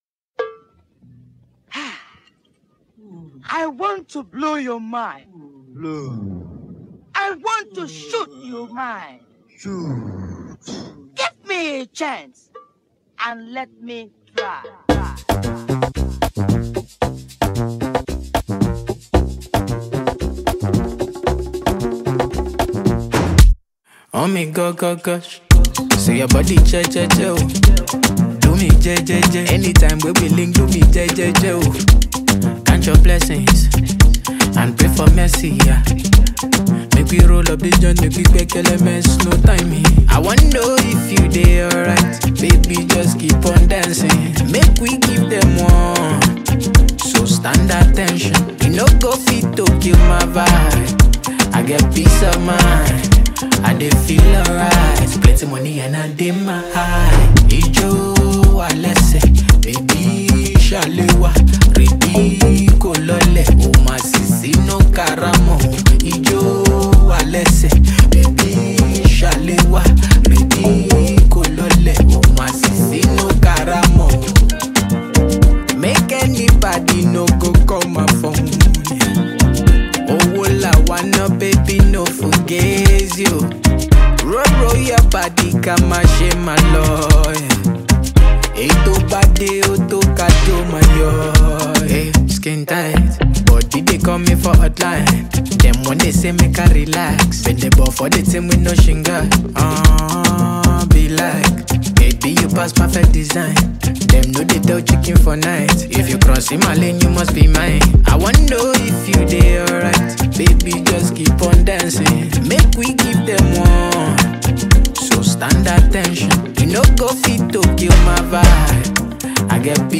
captivating instrumentals
Afrobeats
With nice vocals and high instrumental equipments